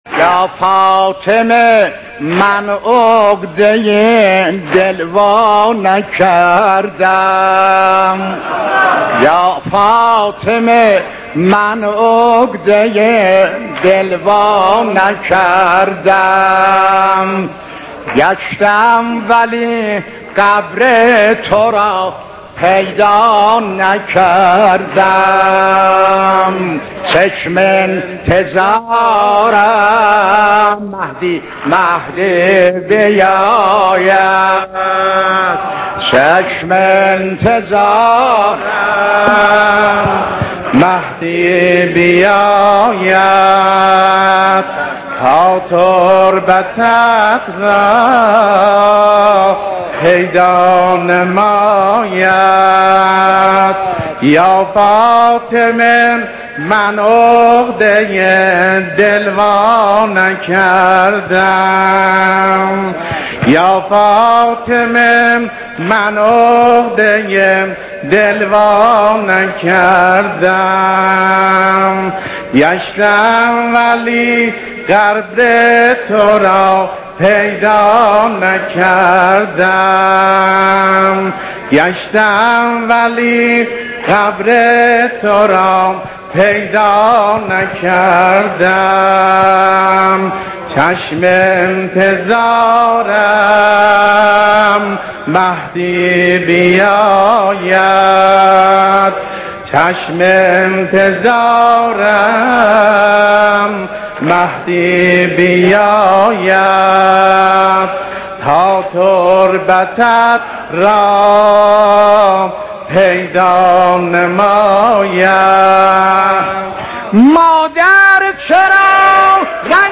متن نوحه سنتی شهادت فاطمه زهرا با صدای سلیم موذن زاده اردبیلی -(یا فاطمه من عقده دل وا نکردم)
نوحه قدیمی فاطمیه